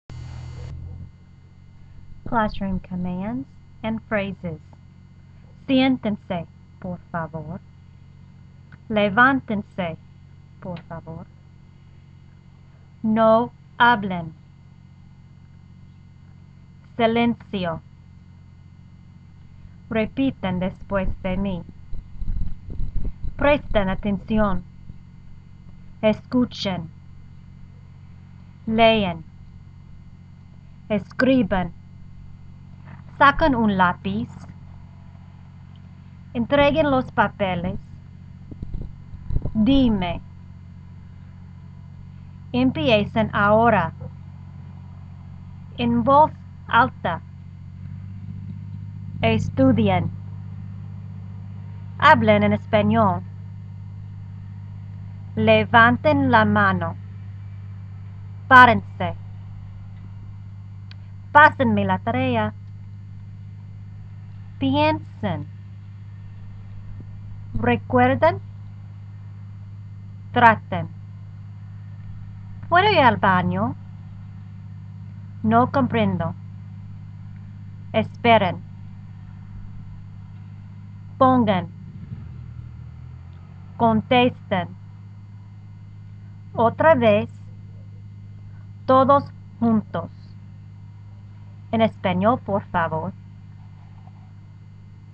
LISTEN to how the phrase is pronounced in Spanish and look at its translation given in English on the list. 4.
Classroom-commands-phrases.wma